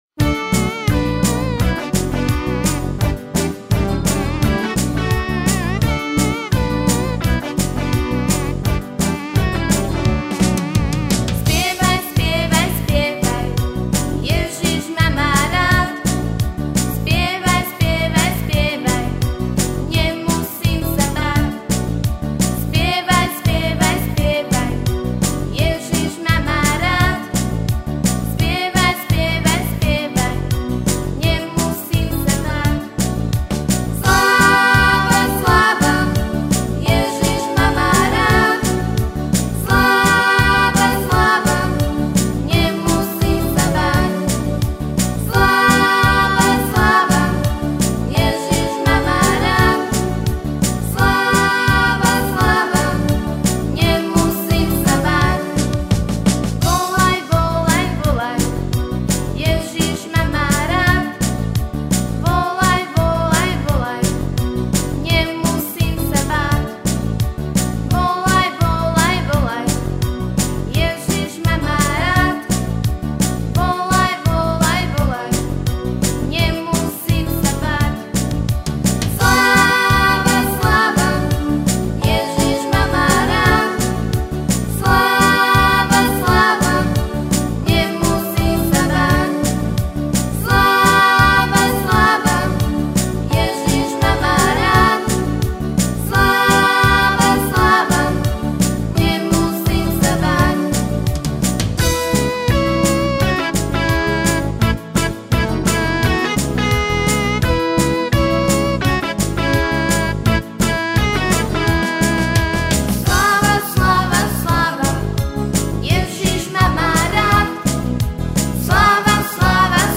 Křesťanské písně
Písně zpívané slovensky